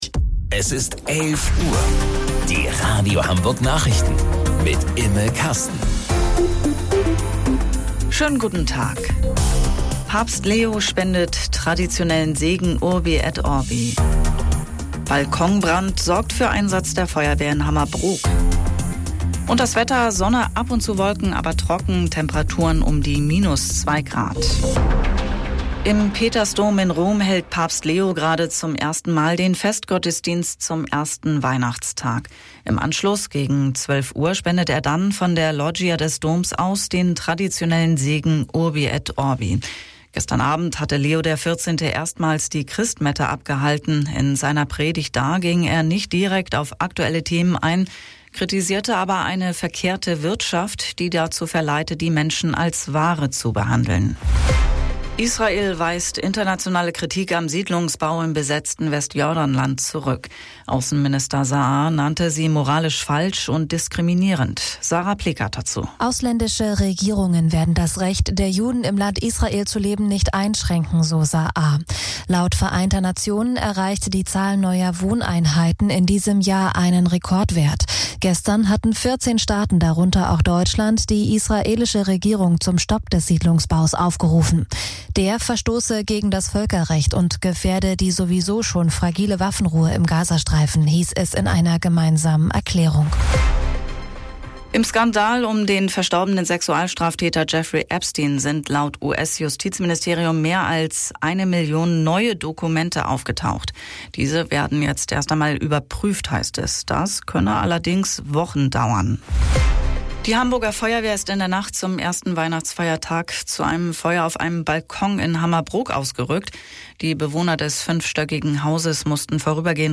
Radio Hamburg Nachrichten vom 25.12.2025 um 11 Uhr